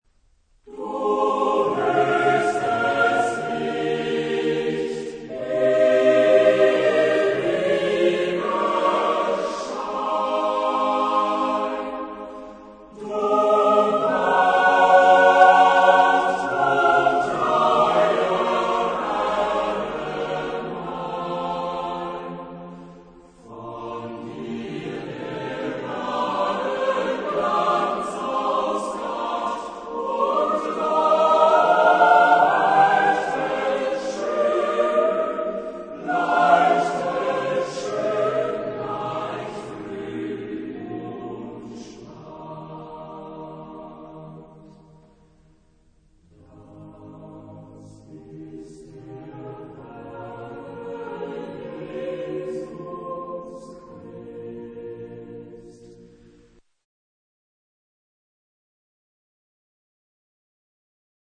Genre-Style-Form: Romantic ; Sacred
Mood of the piece: lively
Type of Choir: SSATBB  (6 mixed voices )
Tonality: E flat major